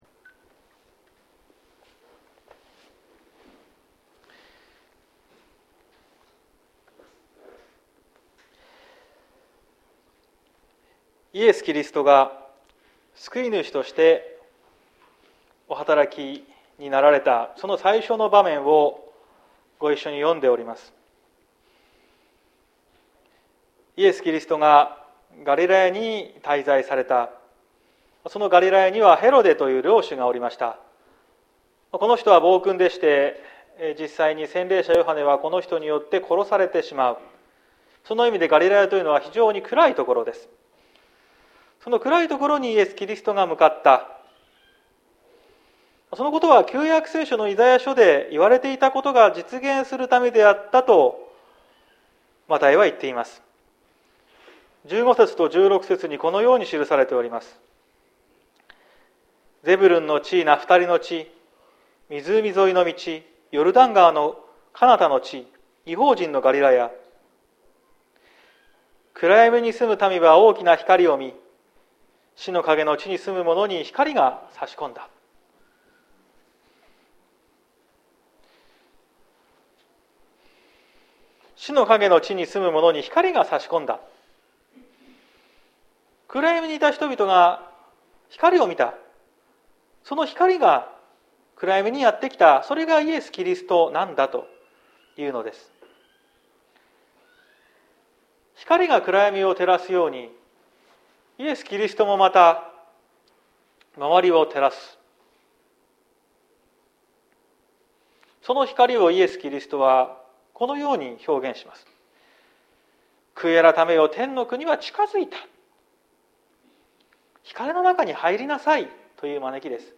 2022年05月15日朝の礼拝「本当の師匠」綱島教会
説教アーカイブ。